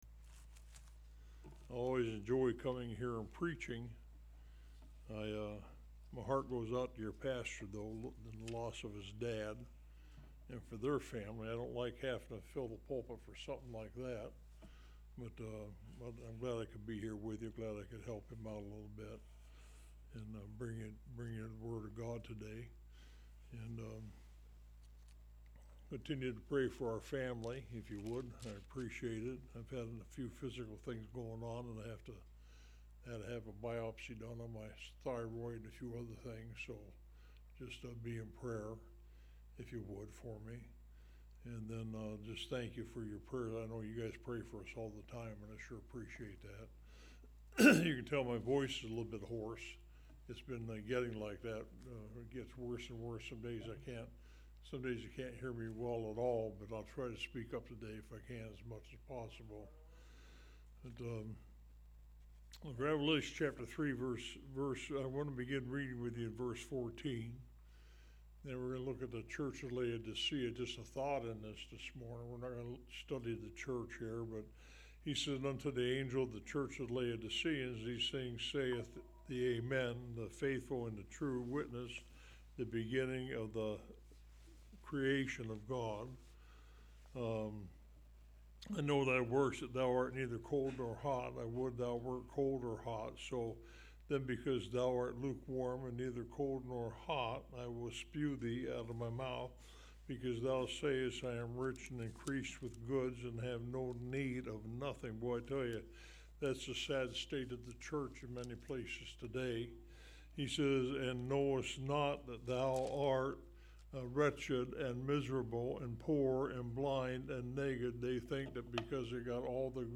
Online Sermons – Walker Baptist Church